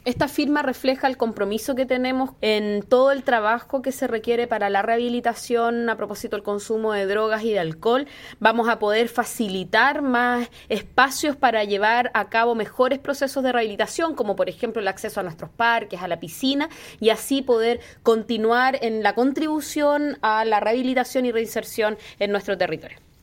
Audio-Alcaldesa-Valdivia-Carla-Amtmann.mp3